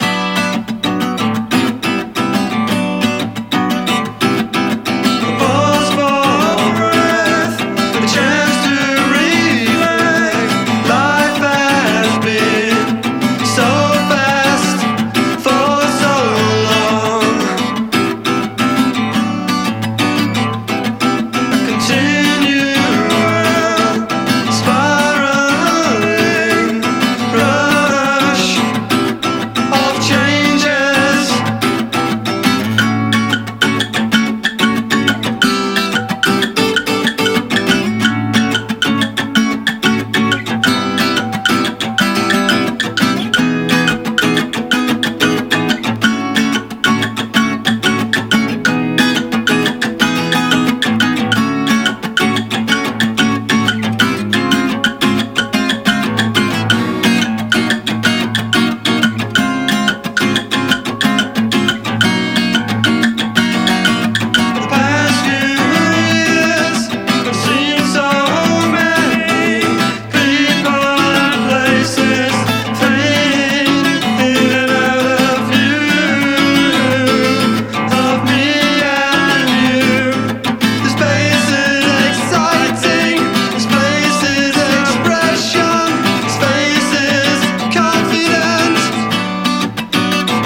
NEO-ACO/GUITAR POP / POST PUNK / NEW WAVE